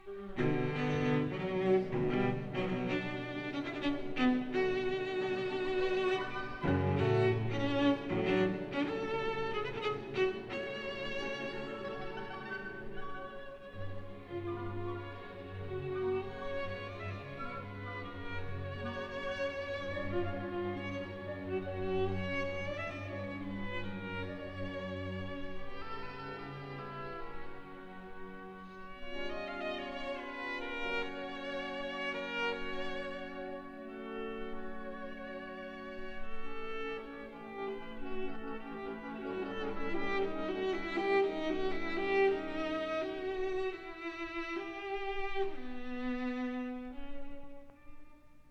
at No. 1 Studio, Abbey Road, London